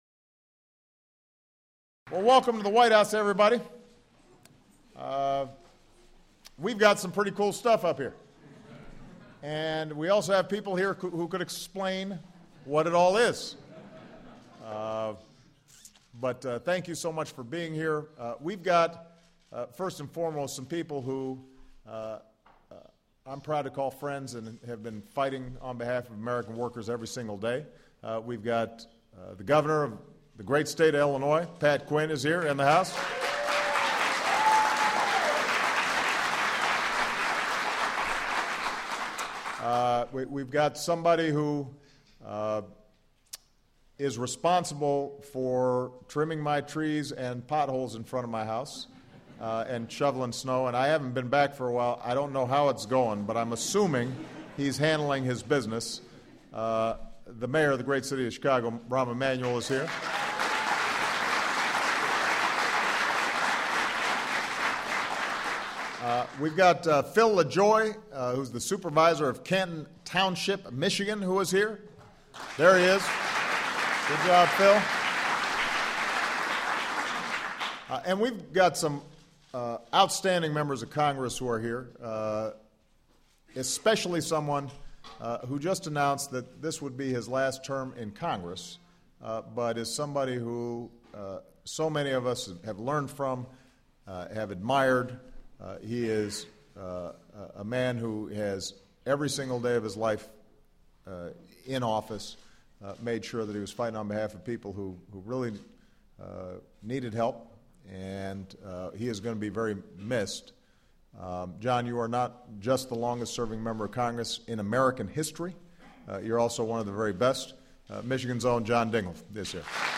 U.S. President Barack Obama speaks on manufacturing innovation in the U.S. economy